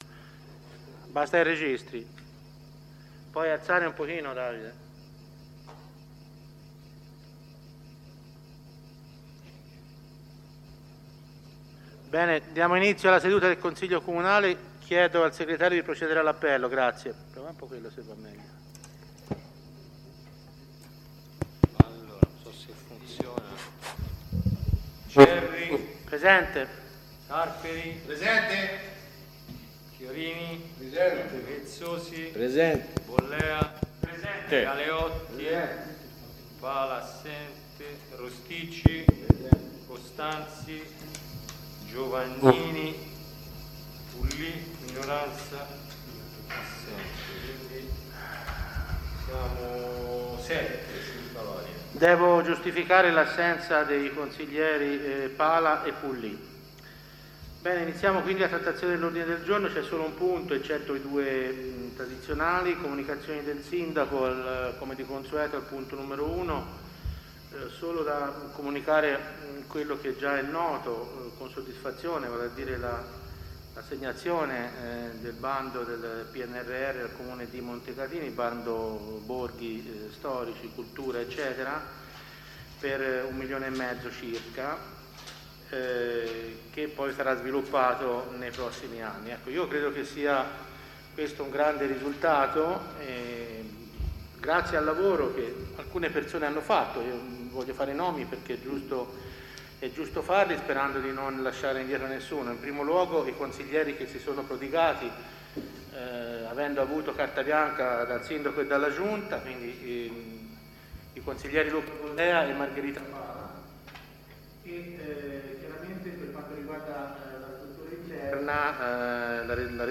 Consiglio Comunale del 14/07/2022 Comune di Montecatini Val di Cecina